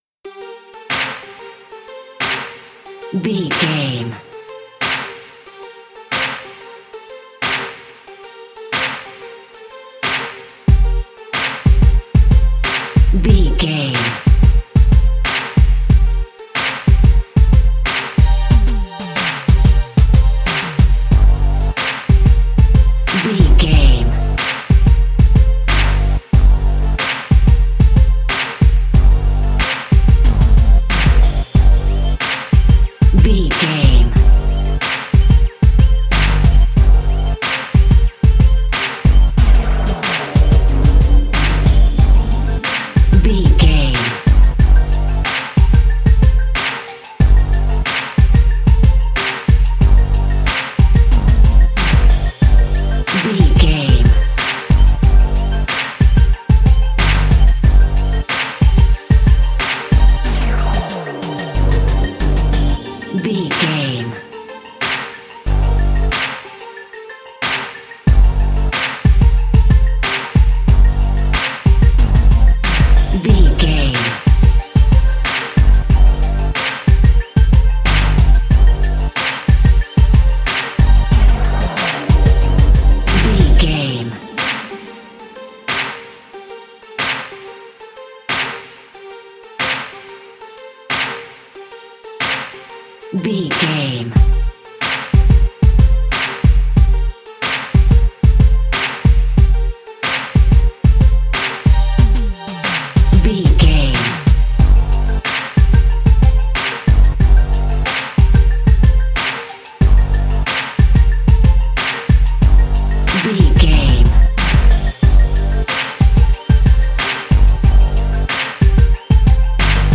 Aeolian/Minor
drum machine
synthesiser
electric piano
percussion
90s